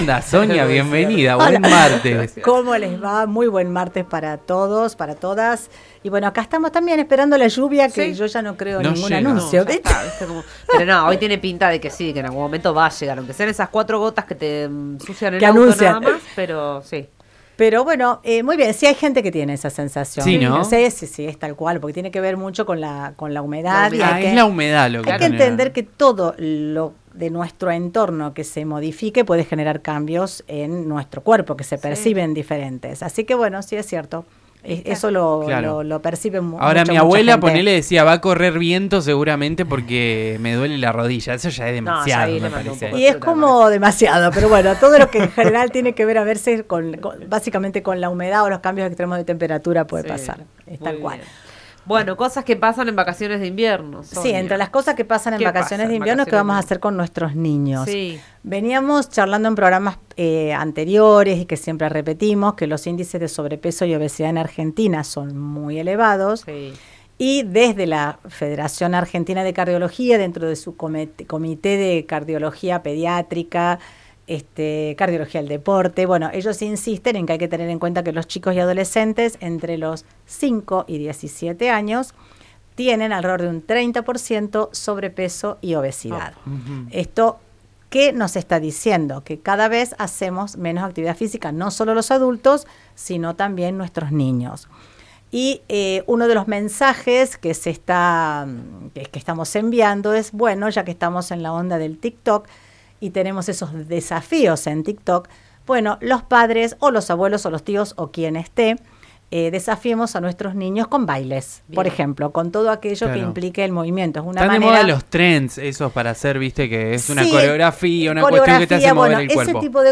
columna de salud